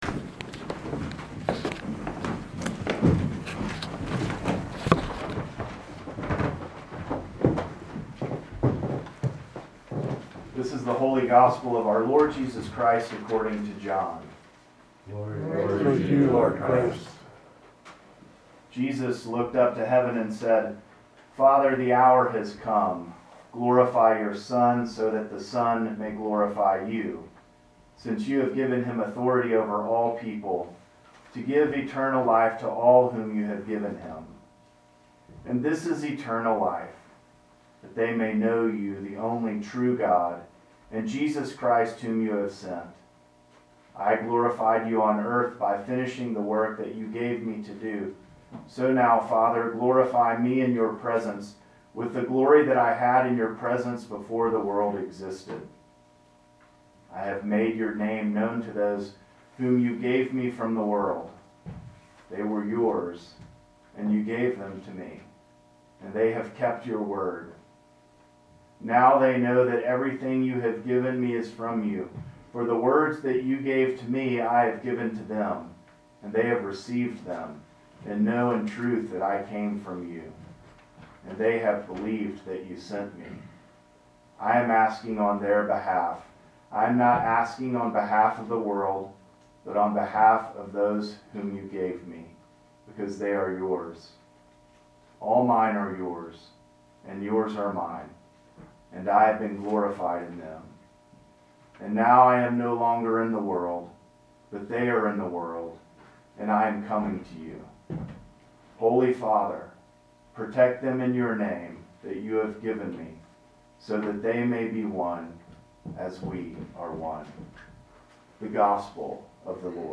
Sermon from St. John's Jackson Hole.